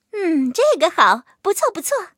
M2中坦强化语音.OGG